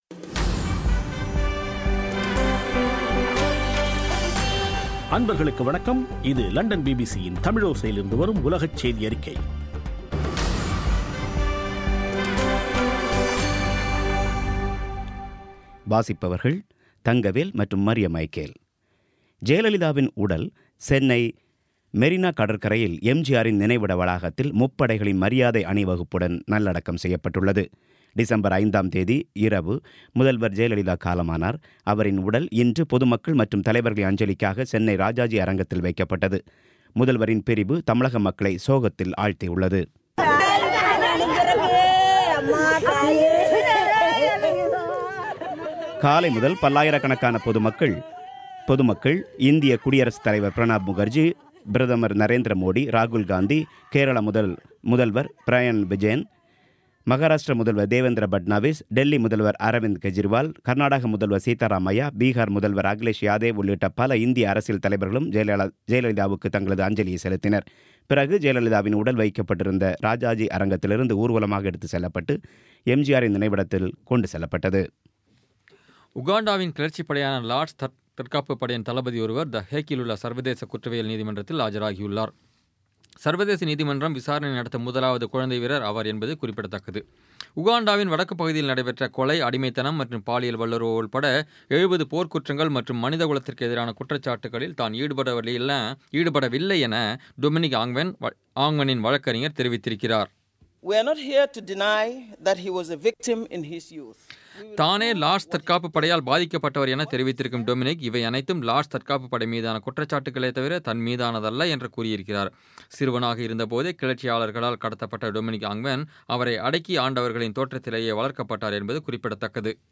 பி பி சி தமிழோசை செய்தியறிக்கை (06/12/16)